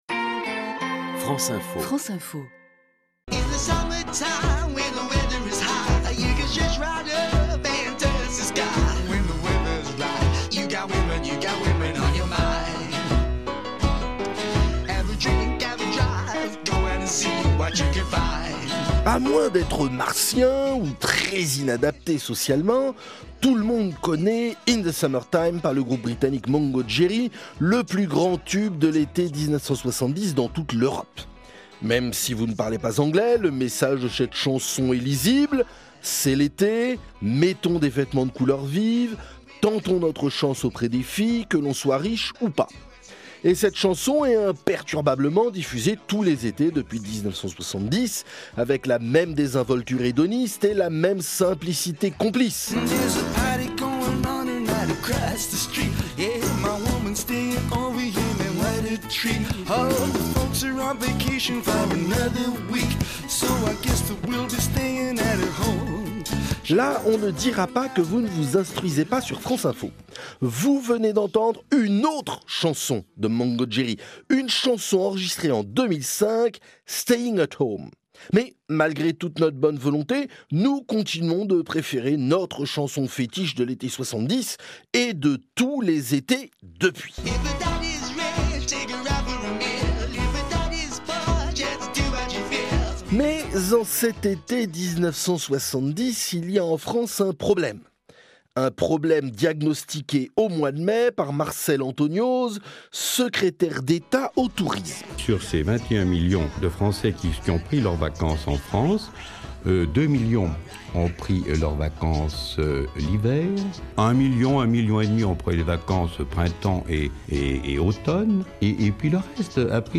diffusées sur France Info